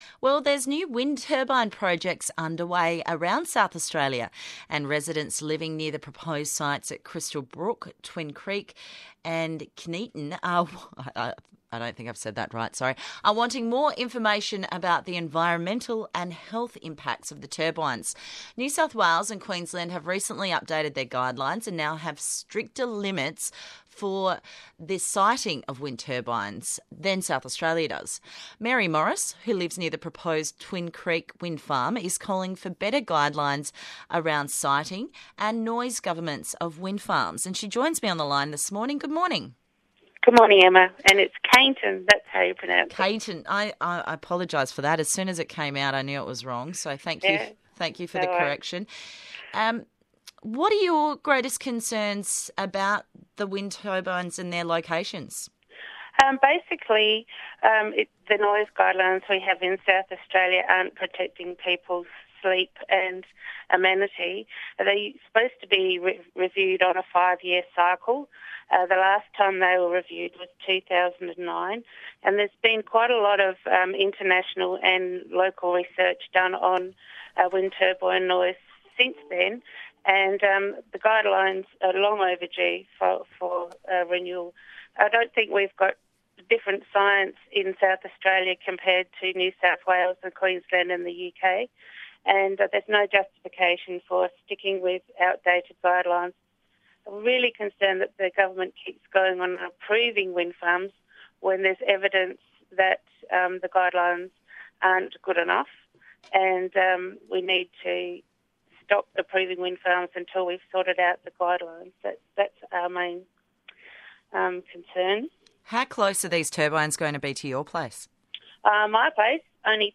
ABC Radio